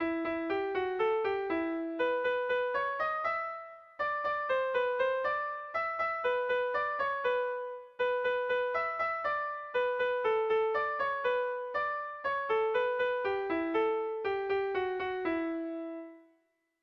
Melodías de bertsos - Ver ficha   Más información sobre esta sección
Zortziko txikia (hg) / Lau puntuko txikia (ip)
A-B-C-D